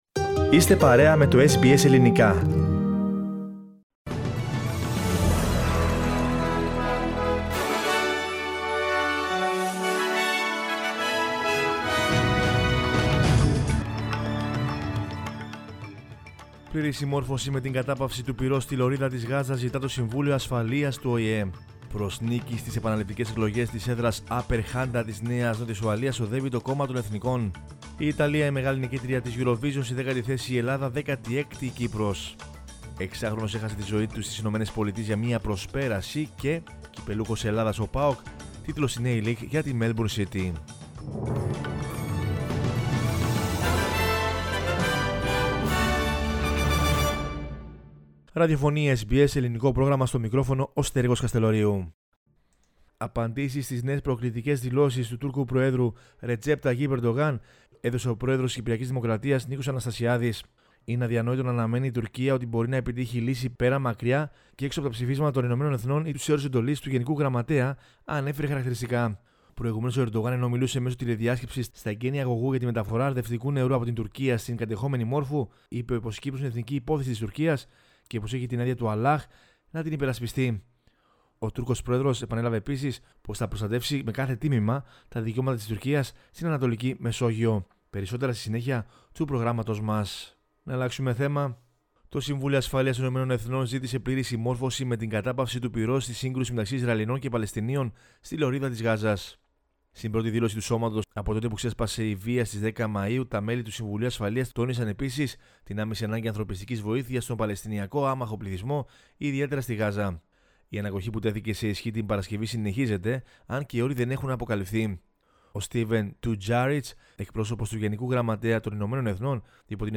News in Greek from Australia, Greece, Cyprus and the world is the news bulletin of Sunday 23 May 2021.